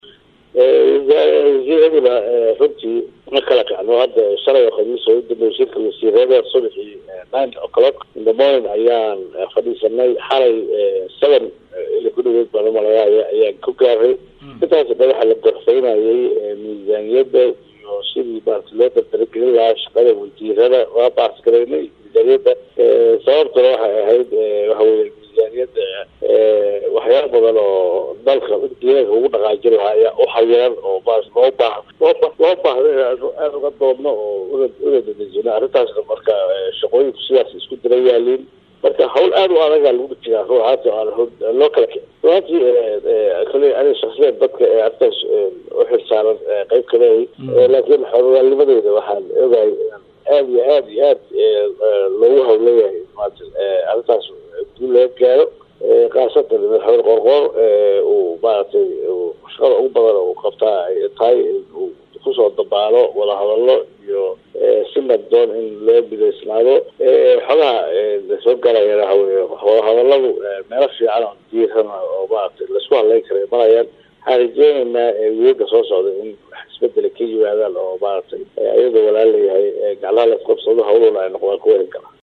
Axmed Shire Falagle oo la hadlay idaacadda Simba ee magaalada Muqdisho ayaa sheegay in madaxweyne Qoor-Qoor uu ku howlan yahay sidii xal siyaasadeed loogu heli lahaa arrimaha Galmudug.
Hoos ka dhageyso codka wasiirka